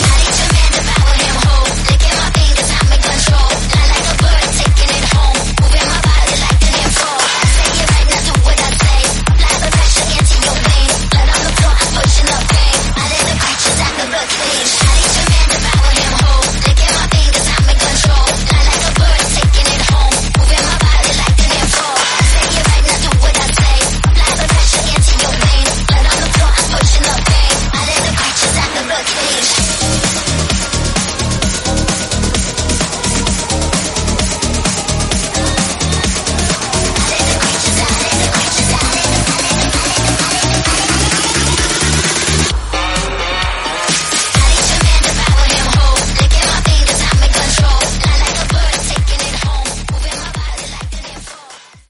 Genre: R & B Version: Clean BPM: 95 Time